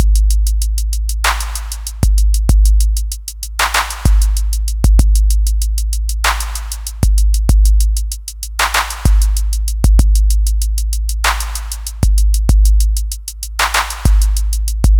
I decided to run a quick comparison between programming a 2 bar drum loop via the Akai MPC 3000 & doing the same via Protools/midi track & a software sampler.
The biggest audible difference is from the MPC’s signal-path. The MPC changes the overall frequency response & can add some grit if pushed.
mpc3000.wav